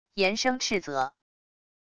严声斥责wav音频